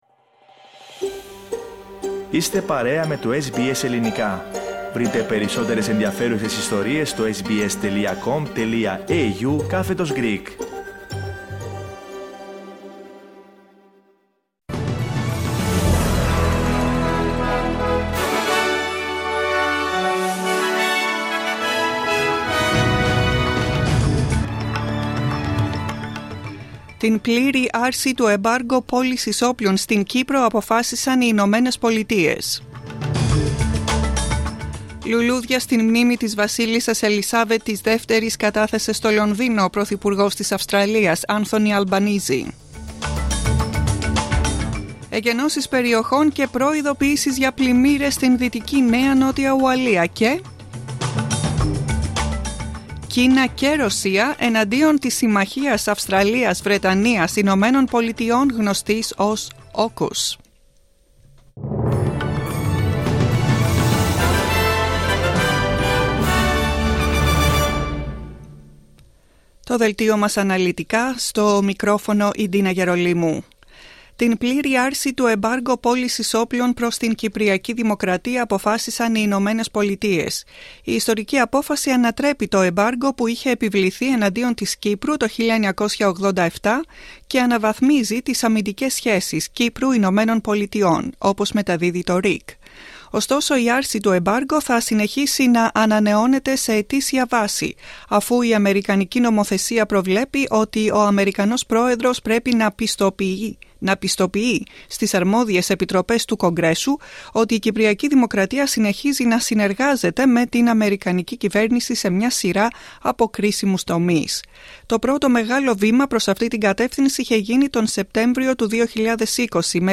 News bulletin in Greek, 17.09,22
Listen to the Greek Program's main bulletin of the day.